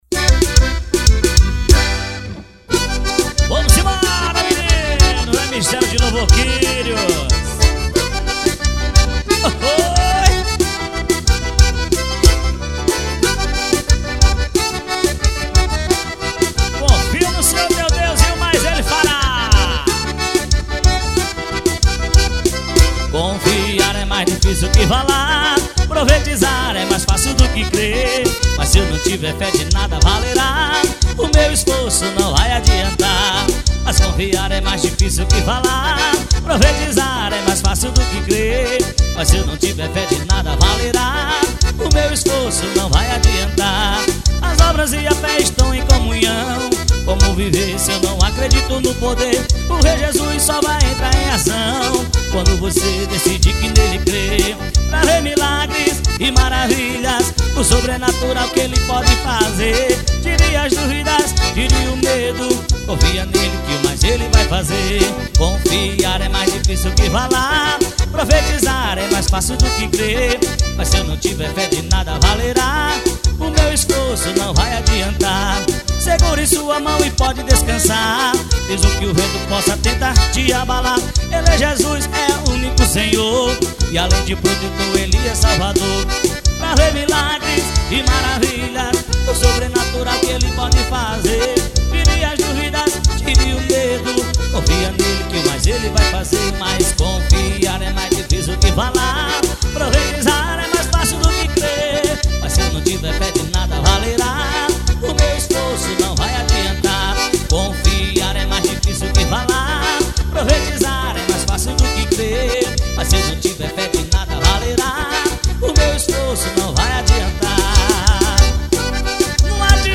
Forró Gospel.